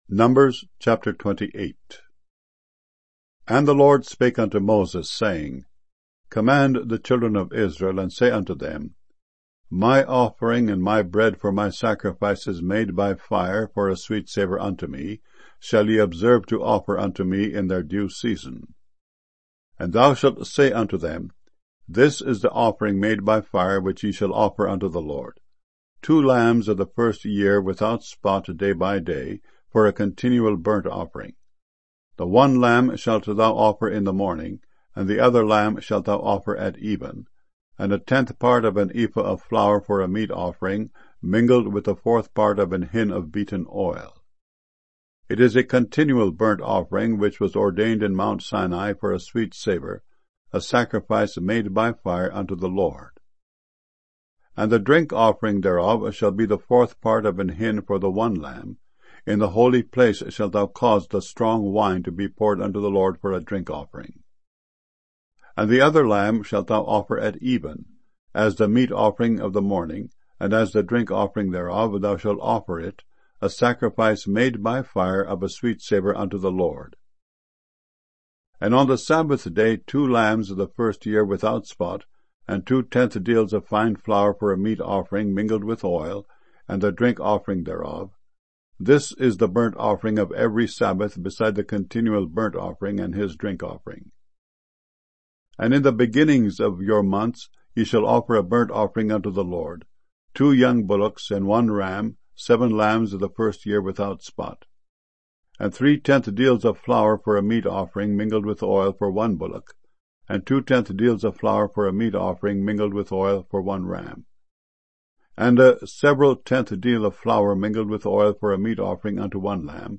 MP3 files mono 32 kbs small direct from wav files